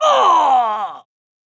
client / bin / pack / sound2 / sound / pc2 / shaman / general / dead.wav
dead.wav